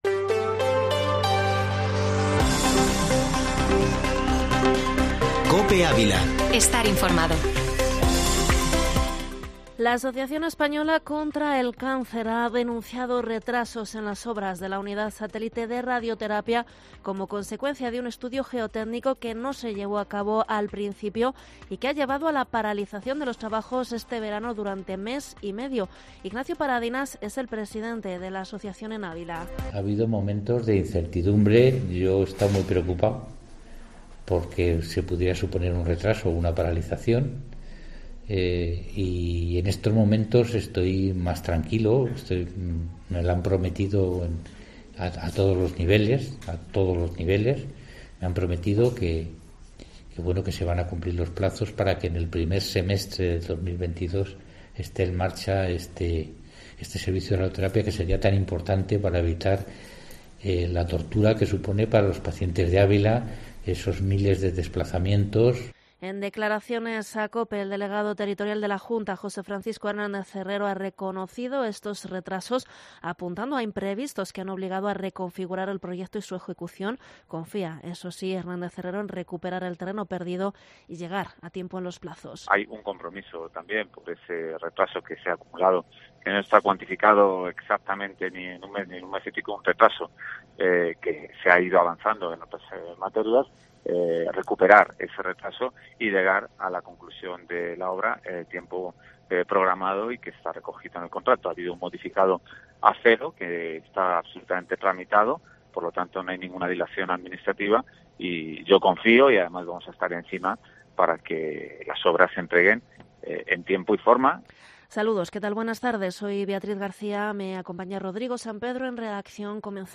informativo Mediodía COPE ÁVILA 28/09/2021